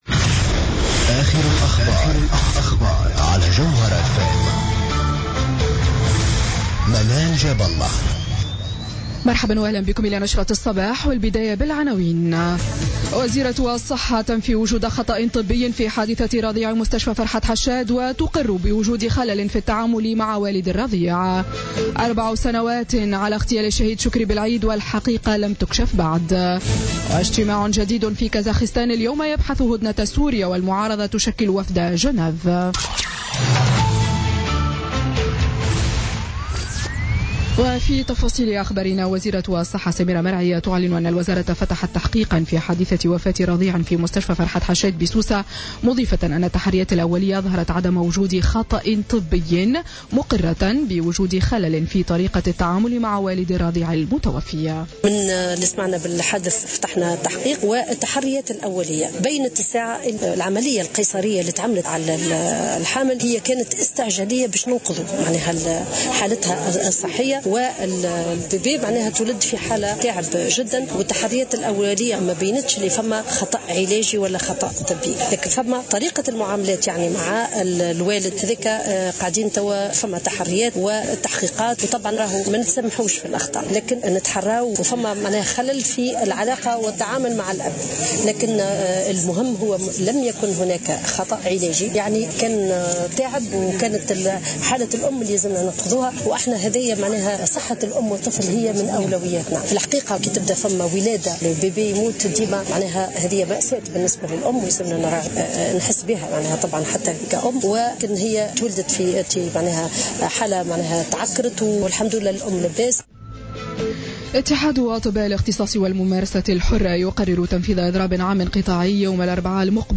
نشرة الأخبار السابعة صباحا ليوم الاثنين 6 فيفري 2017